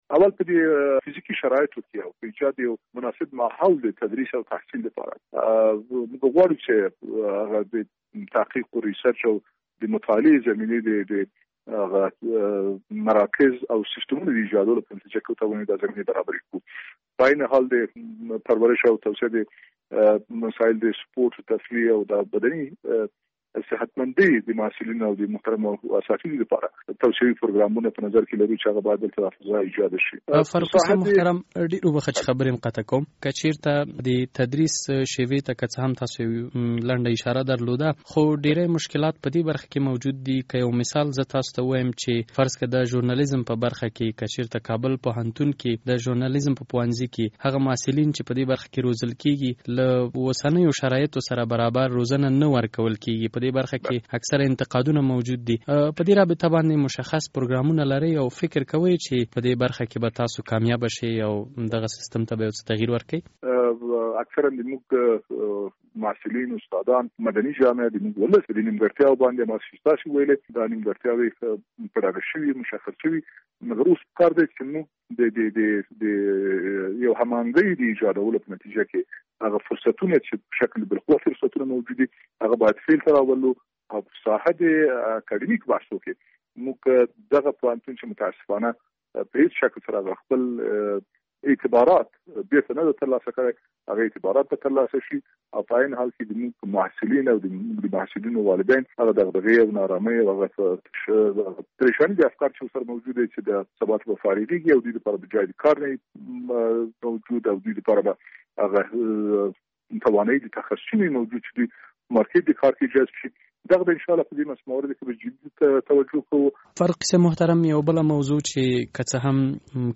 له حمیدالله فاروقي سره مرکه